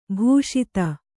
♪ bhūṣita